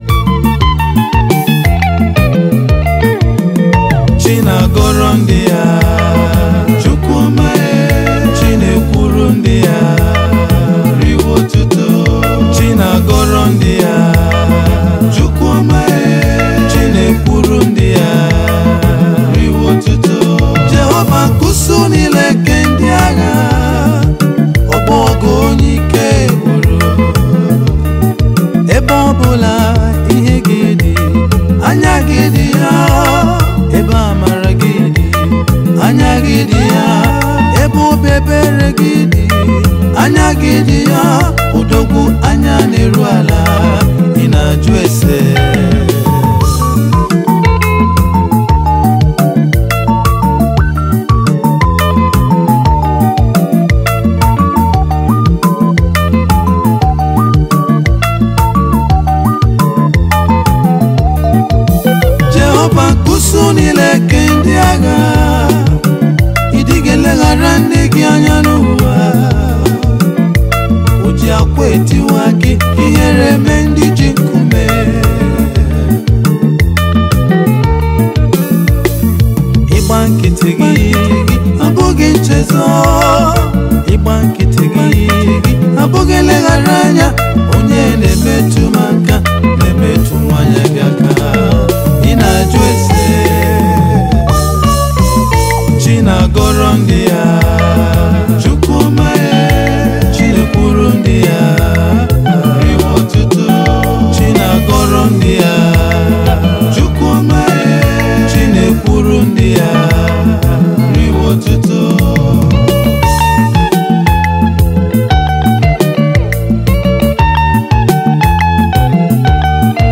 January 20, 2025 Publisher 01 Gospel 0